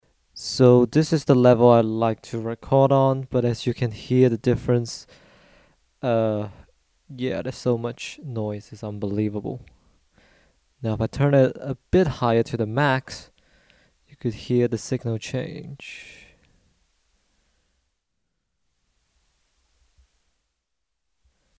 HELP: Signal noise in recording
View attachment 80176 Ok the above is a short 10 sec clip of me talking into the mic thru the pre. The final 3 seconds is just me turning up and down the gain on the pre amp.